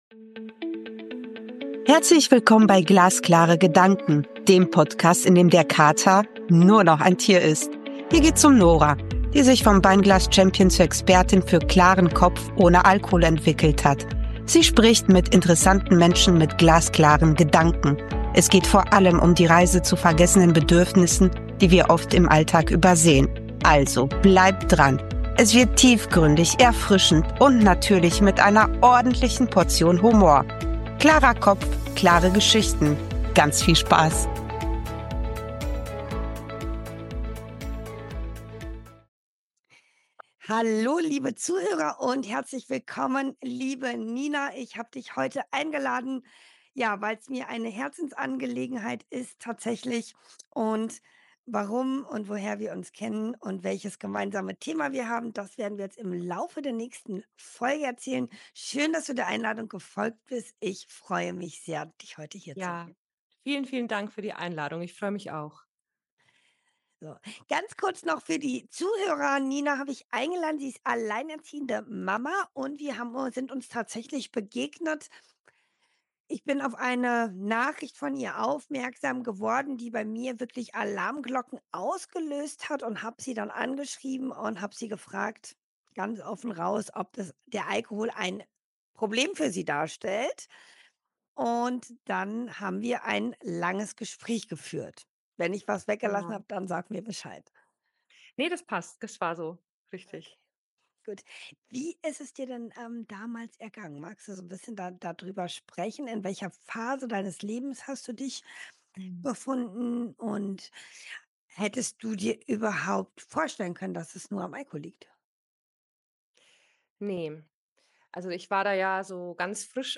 Ein ehrliches, mutiges Gespräch voller Aha-Momente.